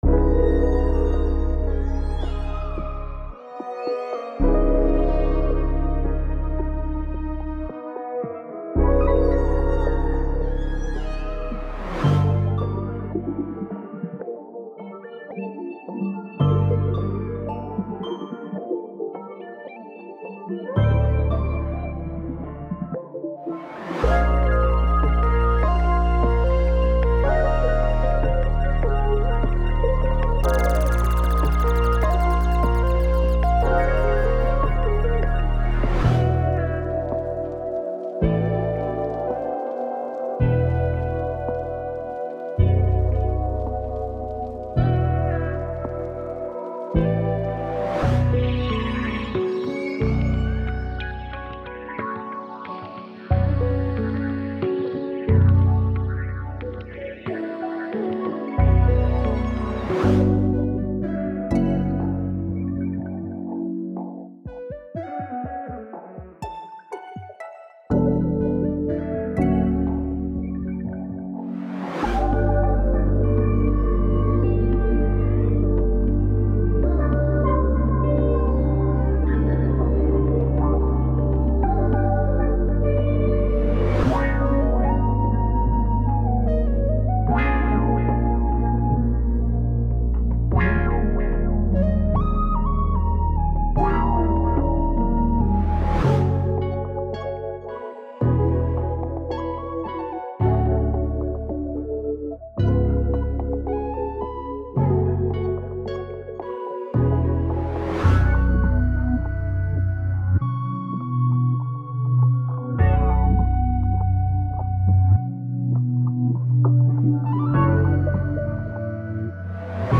With 500 presets, oneshots, loops and phrases, this pack makes it easy to quickly craft vibey, evocative samples for contemporary R&B and hiphop
DEMO